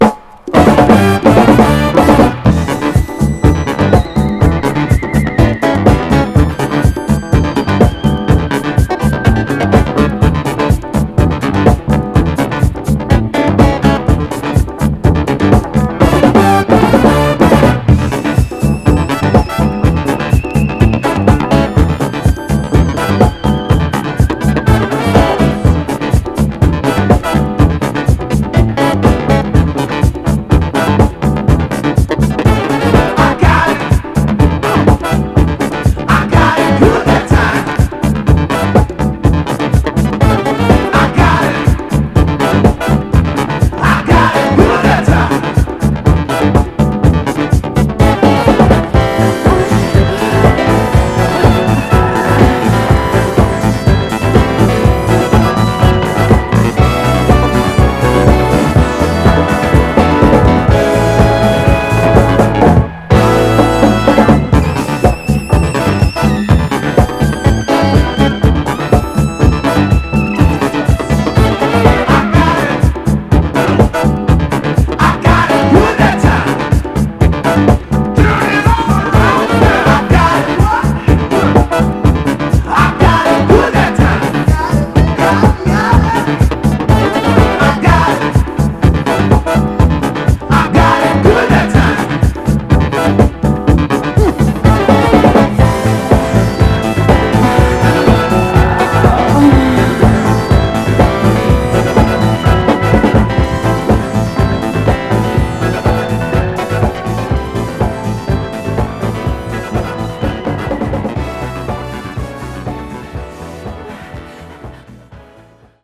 強靭なファンク・ボトムに突き抜ける様なシンセのグルーヴで高揚感たっぷりな、モダン・ブギー・ダンサーの名作です！
盤は細かい表面スレありますが、音への影響は少なくプレイ良好です。
※試聴音源は実際にお送りする商品から録音したものです※